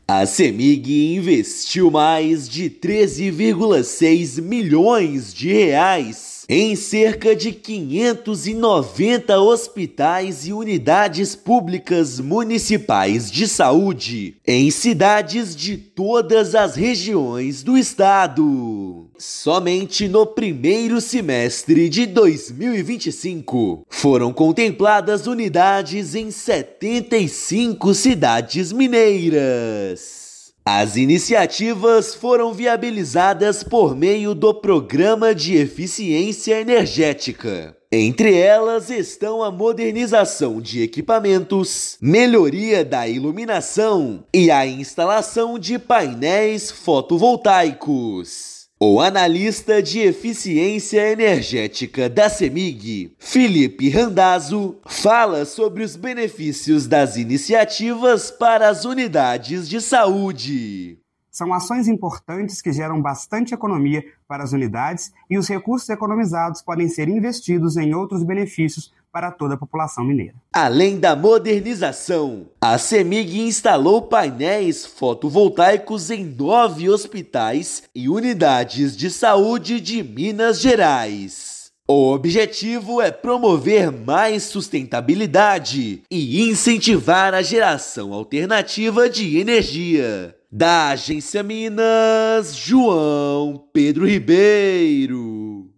Recursos do Programa de Eficiência Energética da Companhia foram destinados à modernização de equipamentos, melhoria da iluminação e instalação de usinas solares fotovoltaicas. Ouça matéria de rádio.